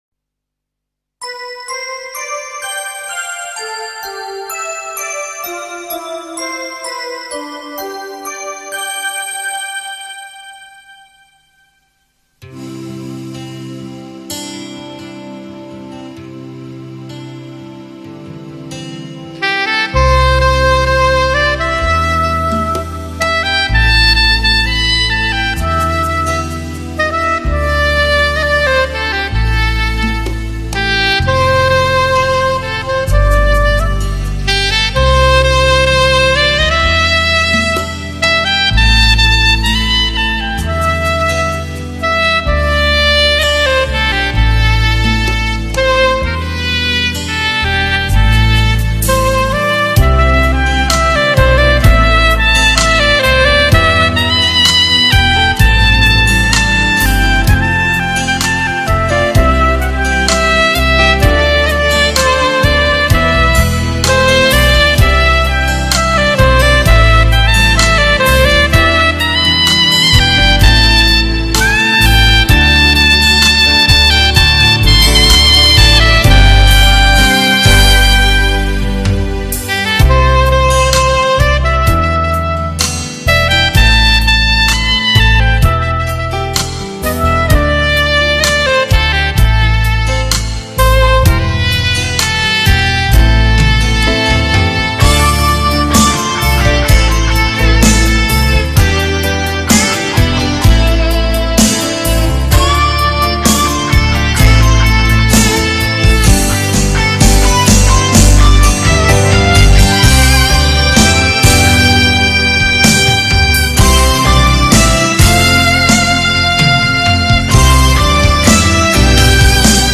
最优美的声音 温馨浪漫的旋律 沉醉其中
萨克斯演奏流行歌曲轻音乐高音质CD唱片。